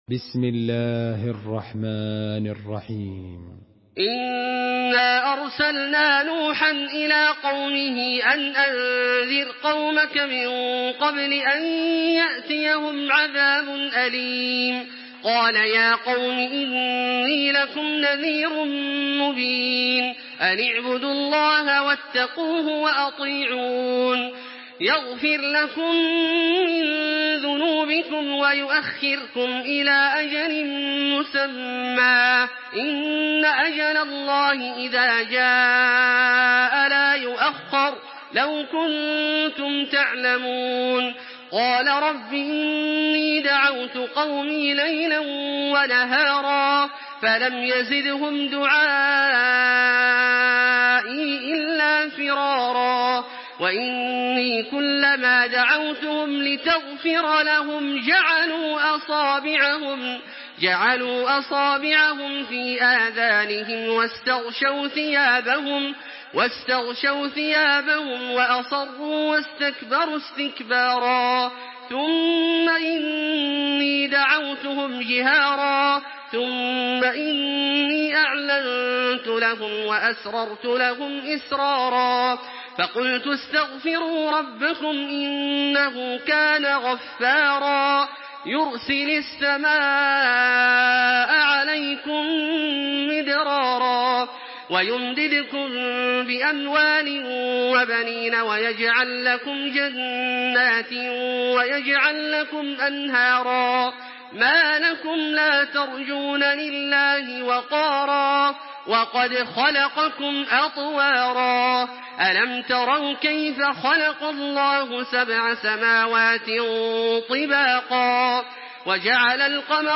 تحميل سورة نوح بصوت تراويح الحرم المكي 1426
مرتل حفص عن عاصم